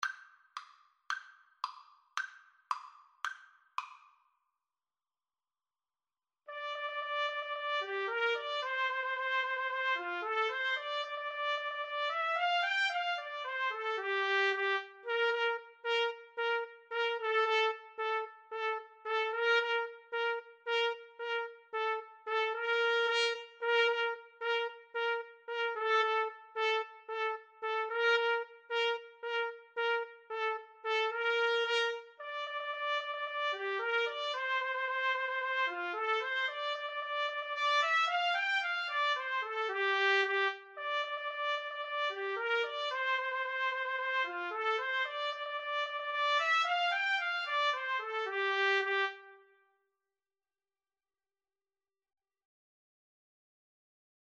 Allegro moderato = c. 112 (View more music marked Allegro)
2/4 (View more 2/4 Music)